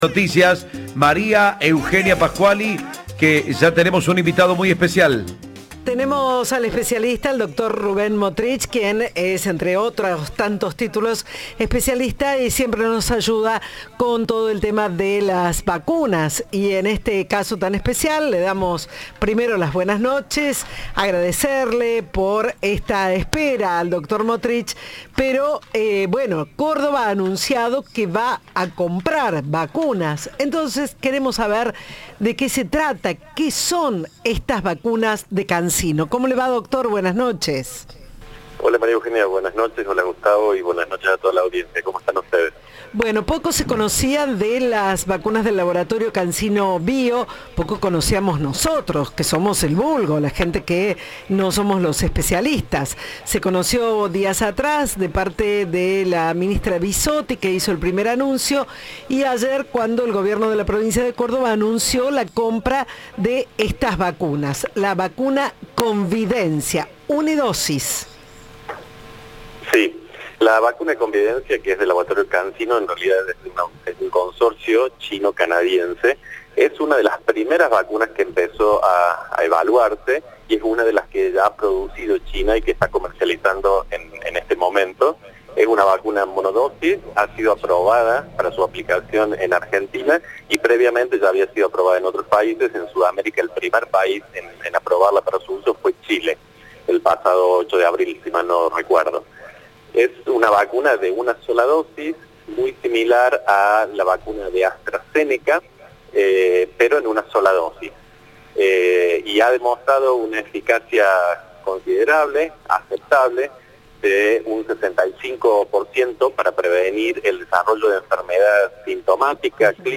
Entrevista de "Turno Noche".